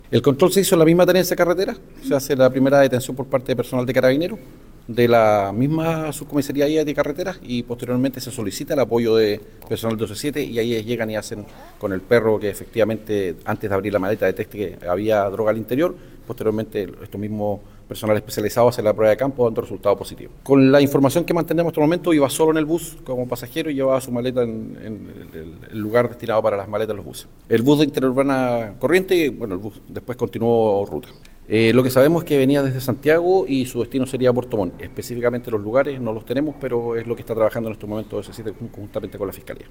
Según explicó el Coronel Valenzuela, estas dosis tenían como destino la comuna de Puerto Montt, donde el sujeto iba como pasajero.